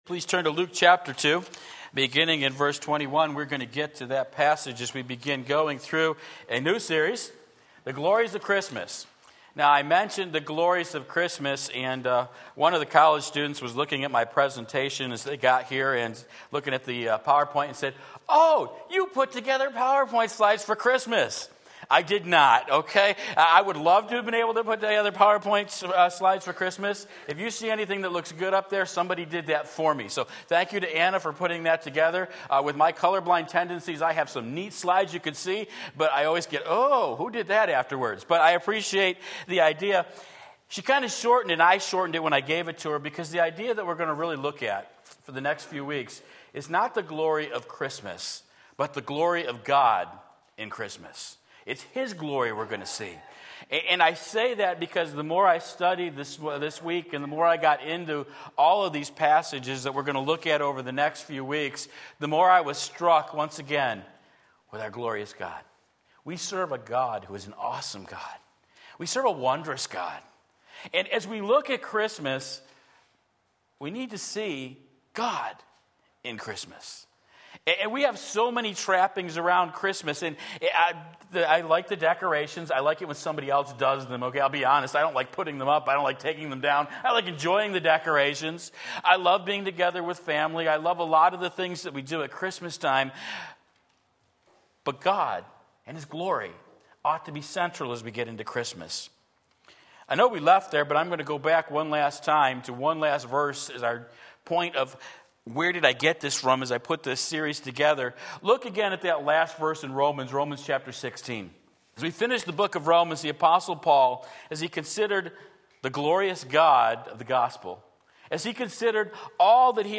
Sermon Link
The Glory of God In a Fulfilled Promise Luke 2:21-38 Sunday Morning Service